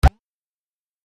دانلود آهنگ تصادف 42 از افکت صوتی حمل و نقل
دانلود صدای تصادف 42 از ساعد نیوز با لینک مستقیم و کیفیت بالا
جلوه های صوتی